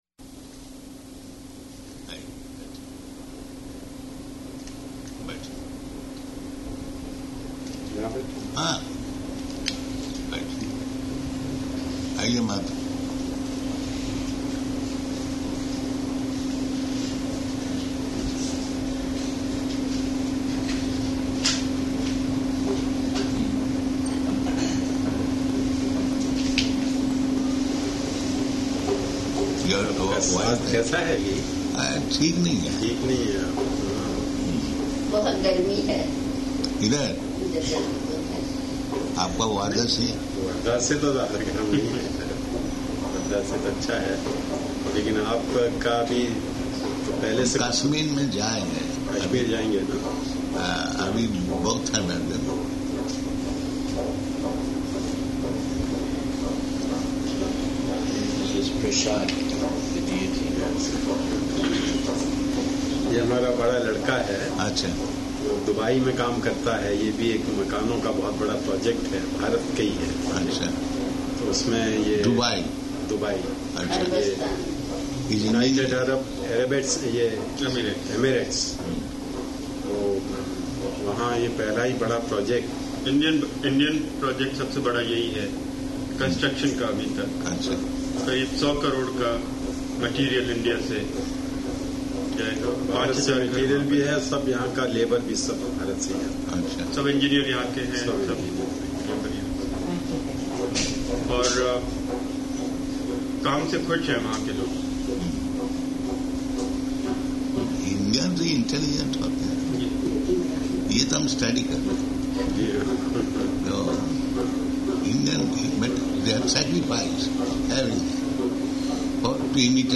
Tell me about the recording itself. -- Type: Conversation Dated: May 2nd 1977 Location: Bombay Audio file